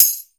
091 - Tambouri.wav